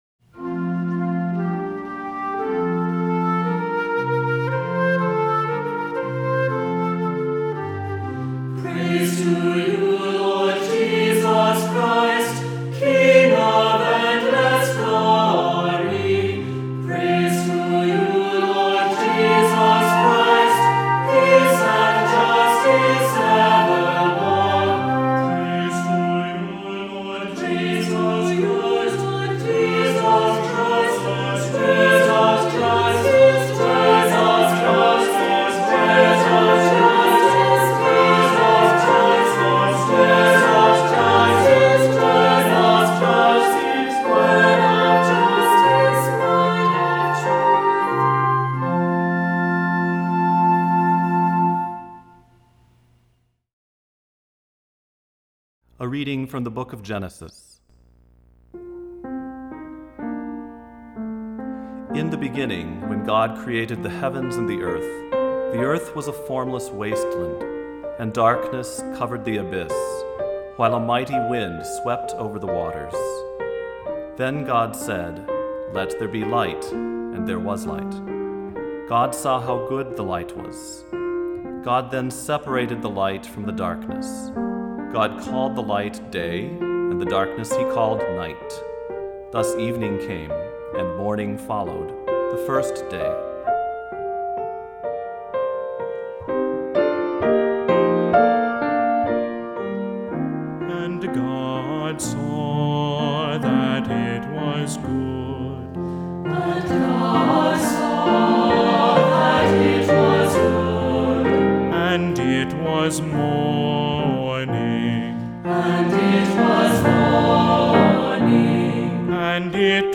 Voicing: 2-part Choir,Assembly,Cantor,Descant